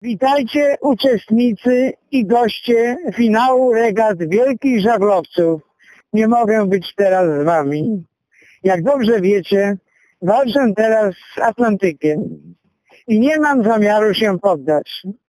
Aleksander Doba pozdrawia ze środka Oceanu
Słynny kajakarz z Polic – Aleksander Doba – pozdrawia ze środka Oceanu i… zaprasza na finał regat Tall Ships Races do Szczecina.